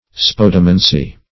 Search Result for " spodomancy" : The Collaborative International Dictionary of English v.0.48: Spodomancy \Spod"o*man`cy\ (sp[o^]d"[-o]*m[a^]n`s[y^]), n. [Gr. spodo`s ashes + -mancy.] Divination by means of ashes.